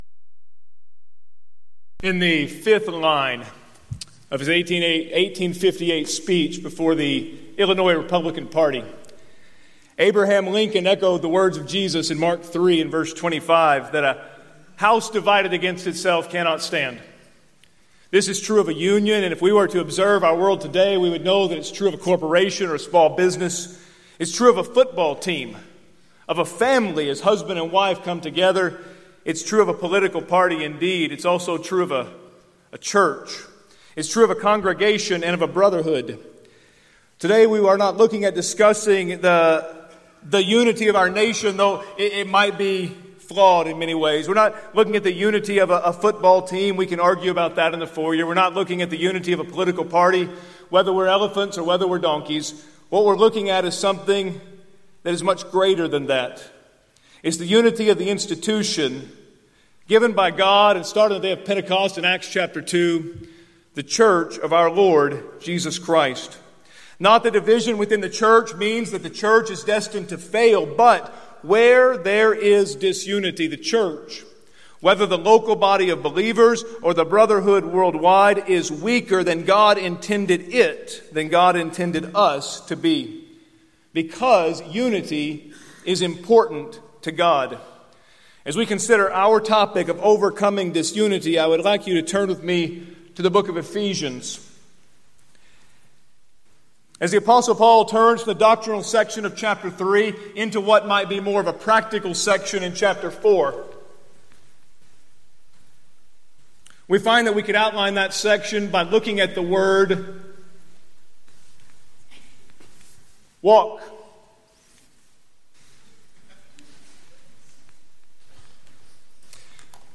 Event: 6th Annual Southwest Spiritual Growth Workshop
lecture